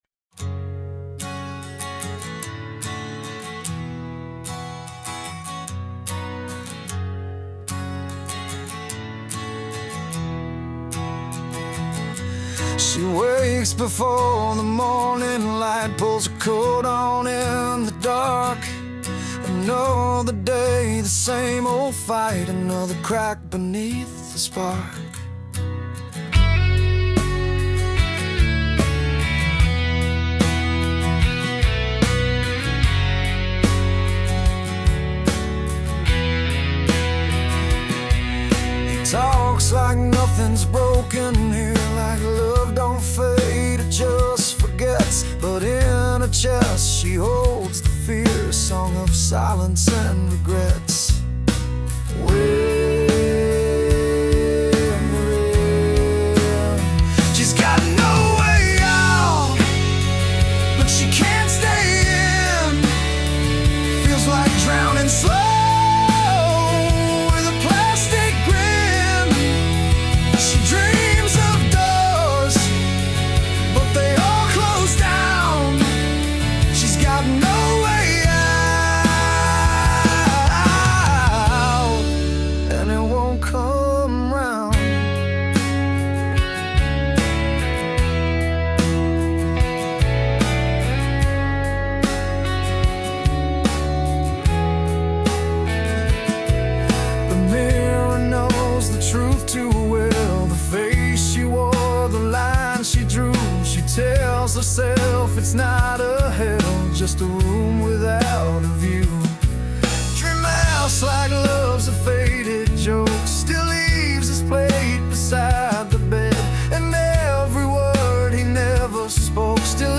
…this one reminded me, as if I was thinking “Nickelback”, don’t know why : )